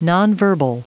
Prononciation du mot nonverbal en anglais (fichier audio)
Prononciation du mot : nonverbal